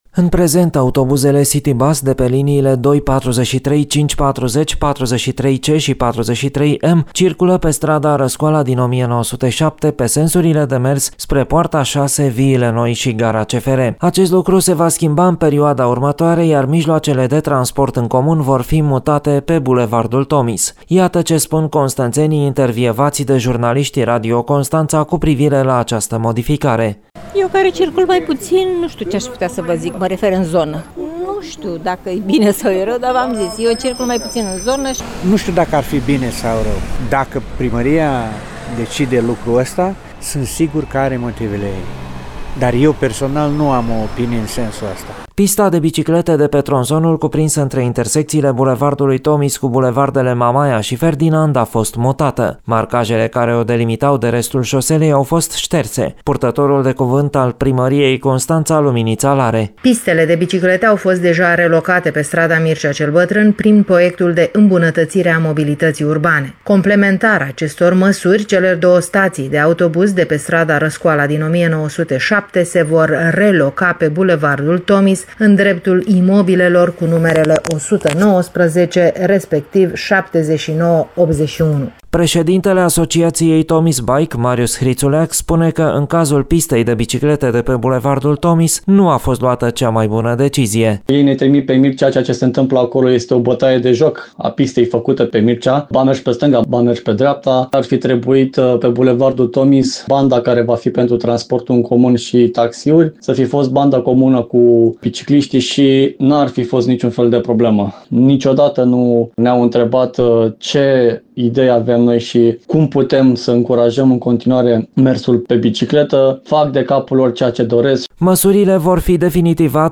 Ce spun constănțenii despre măsura luată de municipalitate